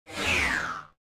dronesWoosh.wav